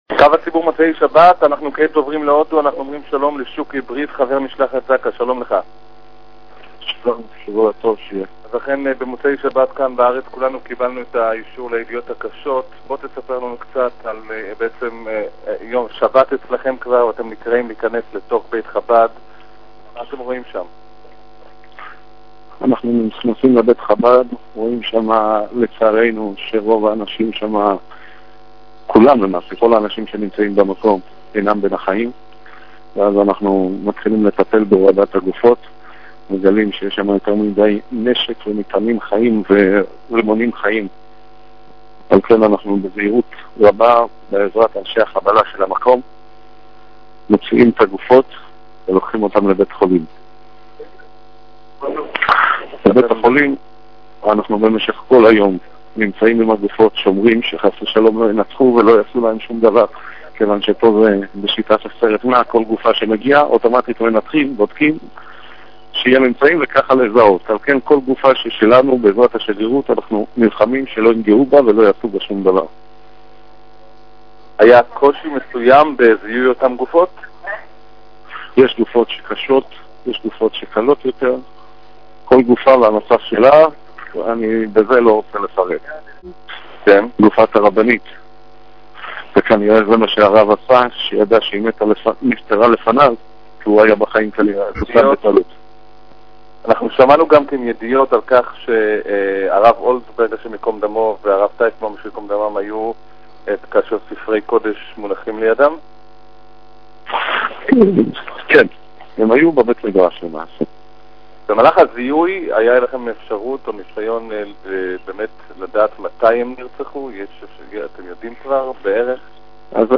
בראיון לקו הציבור